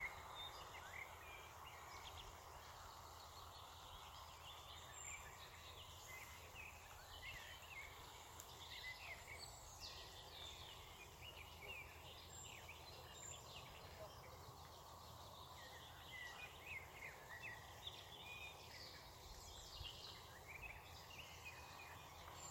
Blackbird, Turdus merula
StatusSinging male in breeding season